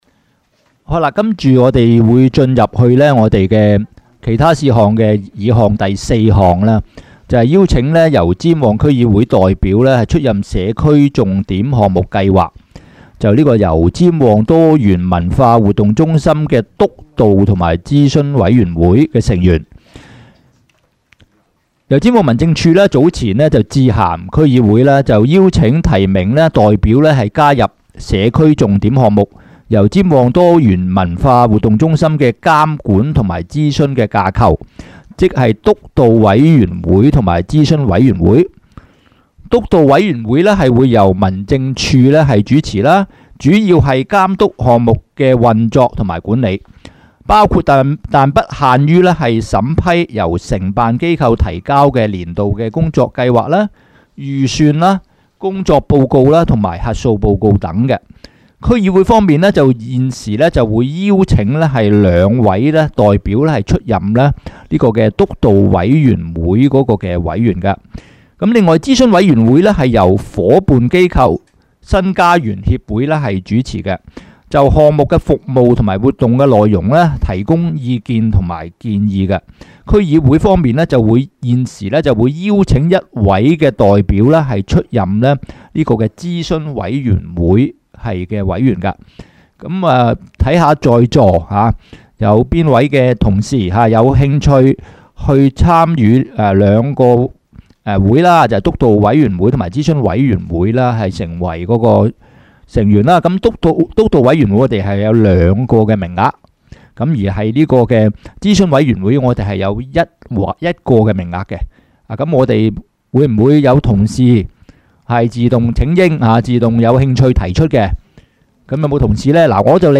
区议会大会的录音记录
油尖旺区议会会议室